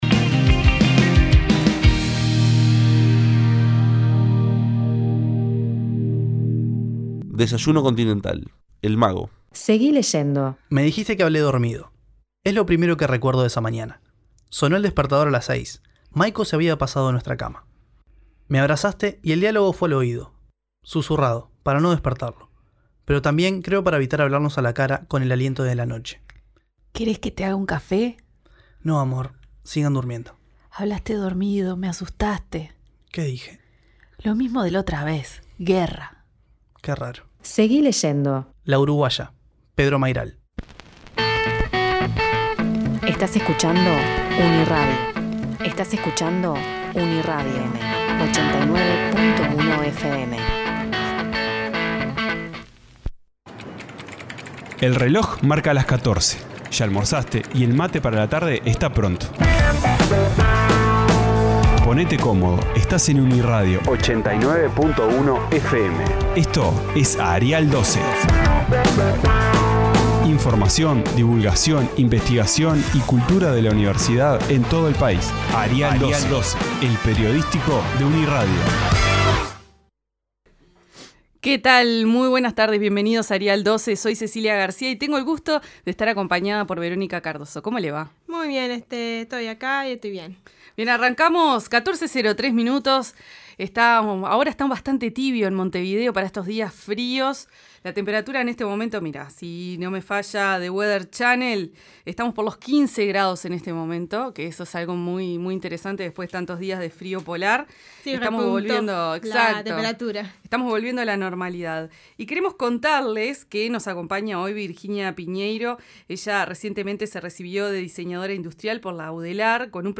En contacto telefónico